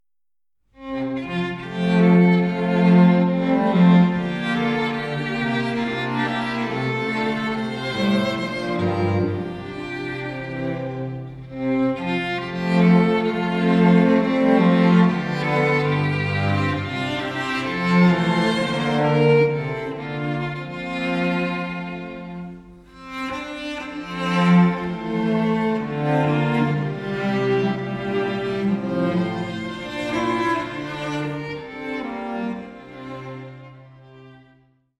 Streichensemble